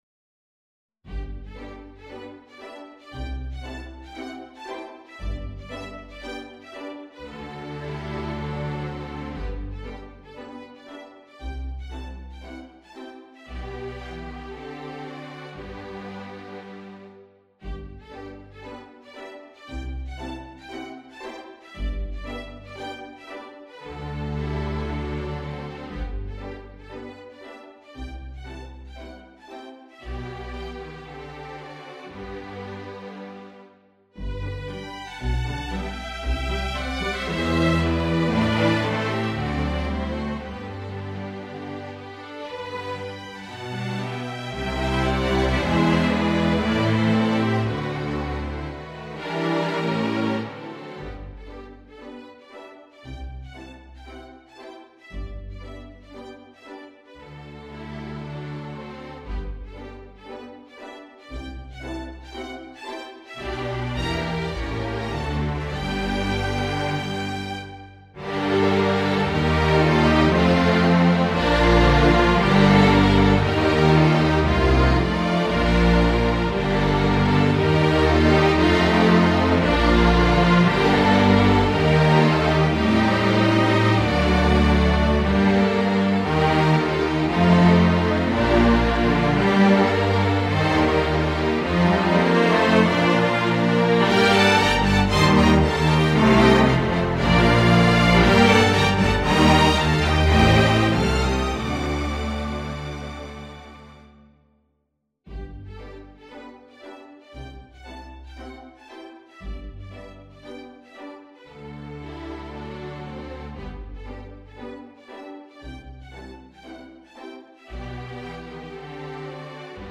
for string orchestra
ORCHESTRATION
Violin 1, Violin 2, Viola, Cello, Bass